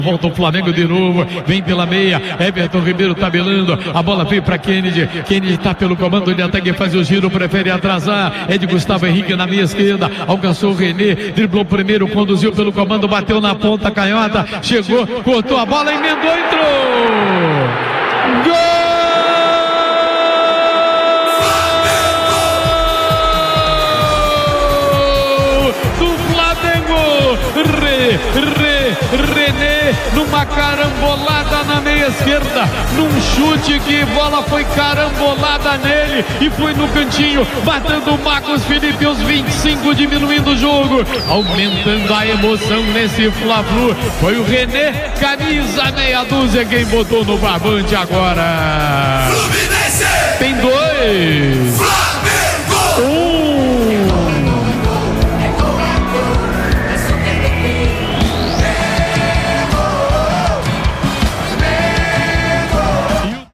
Ouça os gols da vitória do Fluminense sobre o Flamengo pelo Brasileirão com a narração do Garotinho